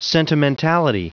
Prononciation du mot sentimentality en anglais (fichier audio)
Prononciation du mot : sentimentality